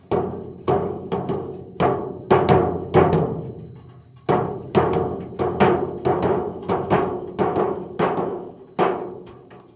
두드리는06.mp3